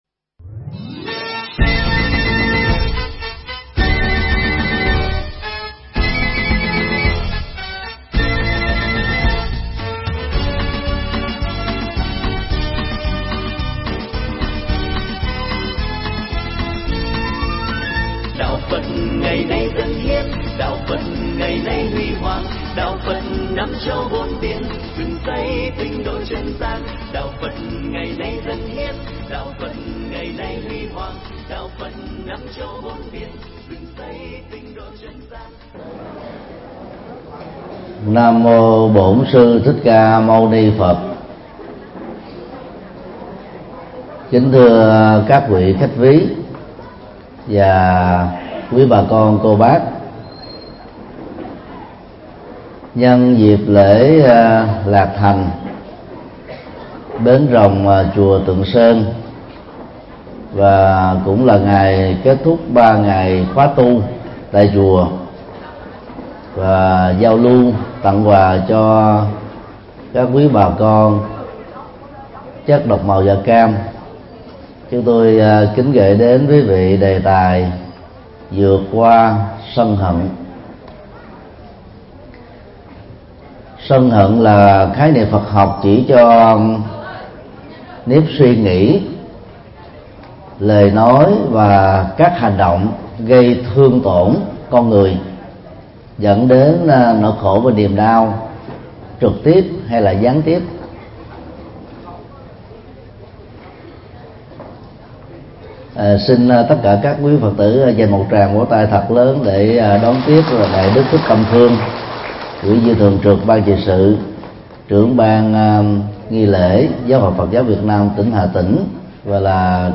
Vượt Qua Sân Hận - Mp3 Thầy Thích Nhật Từ Thuyết Giảng
Mp3 Thuyết Giảng Vượt Qua Sân Hận -Thầy Thích Nhật Từ Giảng tai chùa Tượng Sơn, ngày 11 tháng 1 năm 2015